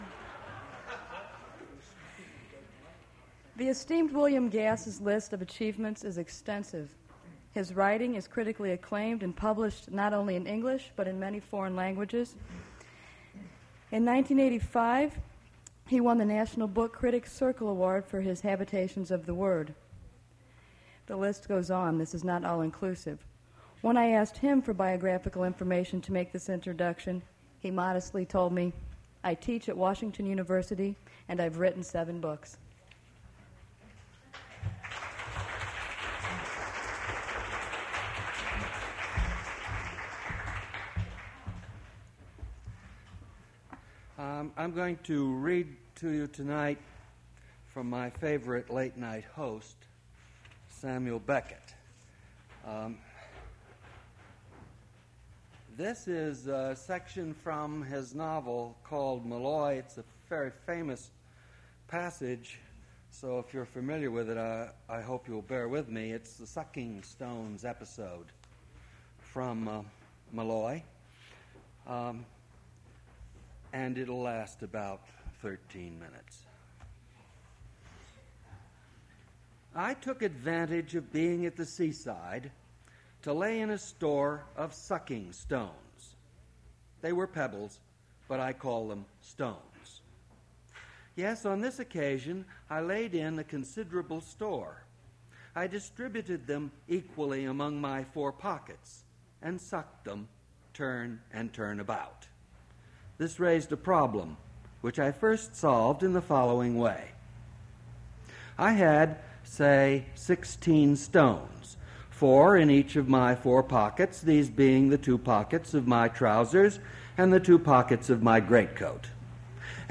Attributes Attribute Name Values Description William Gass prose reading at Duff's Restaurant. Recording Index: Gass reads prose from "Molloy" by Samuel Beckett.
Source mp3 edited access file was created from unedited access file which was sourced from preservation WAV file that was generated from original audio cassette.